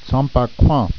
t-some bah koo-ehn